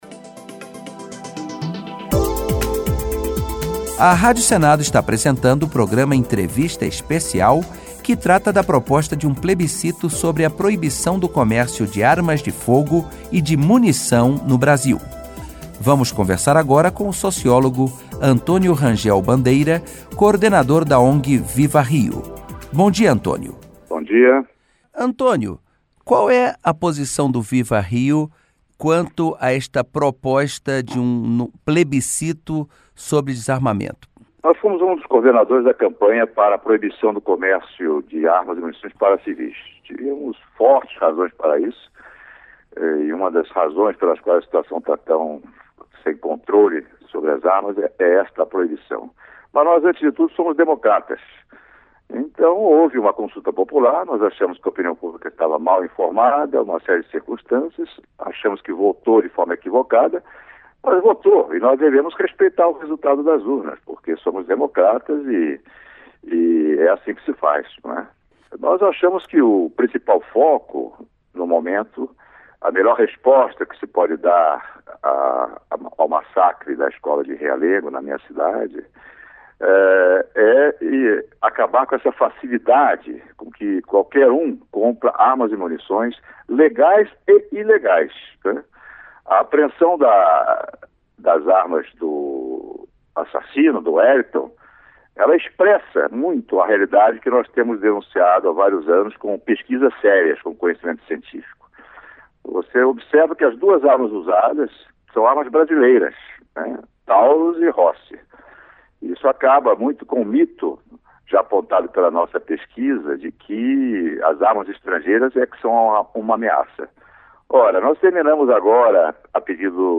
Entrevista com o senador Renan Calheiros (PMDB-AL).